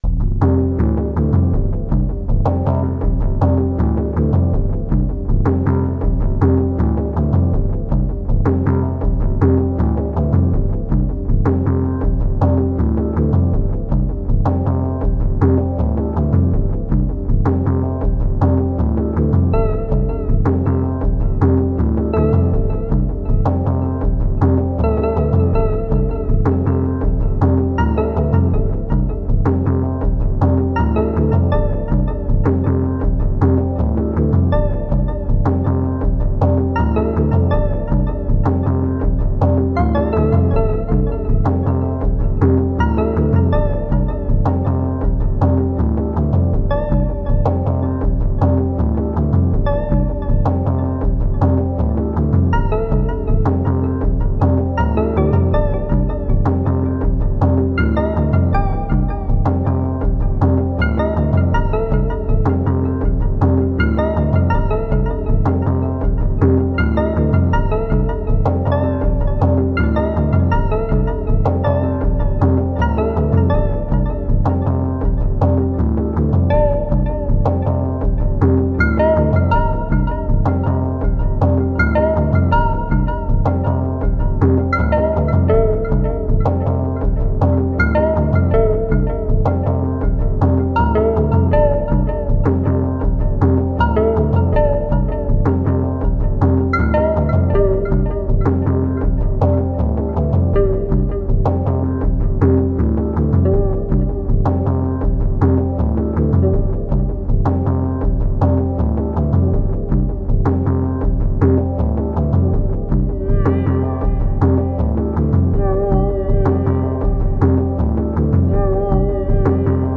Here I have 3 chord tracks, 2 doubled, rhythm guitar type of sound with pitch offset and roughly the same sound playing even higher on top. You can tell it’s live, because it’s mindless and out of time…
Sounds are in 5ths for this guitar vibe and also there is slow ramp lfo on waveform for each sound
There is also a birst of quick-fading high rate mod on tune, to get this more pronounced attack on the sounds
Towards the end I mess with tempo and delay
This was almost like psychedelic krautrock of some kind :slightly_smiling_face: Sounds quite guitar-like, at least on my phone.